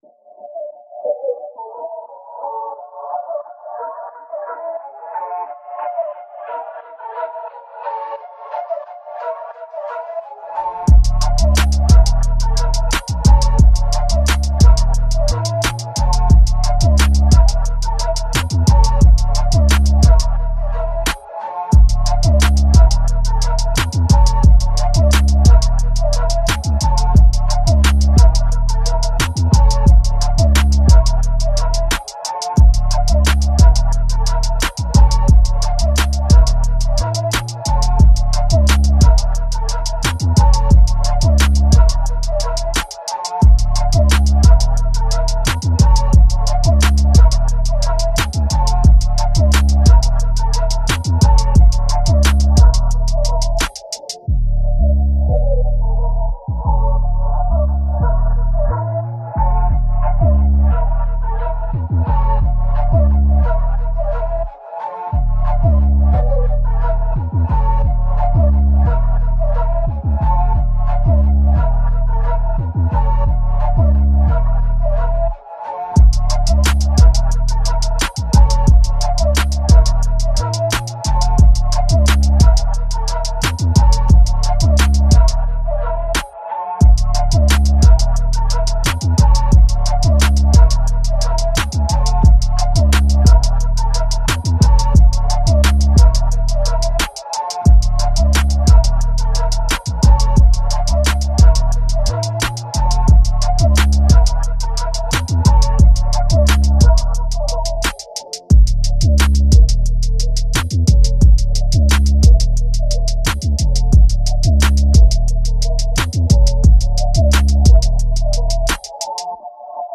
Getting rid of muzzle punching and barking in the “Guard”. He loves to muzzle fight and tries to fight in seated “Guard”.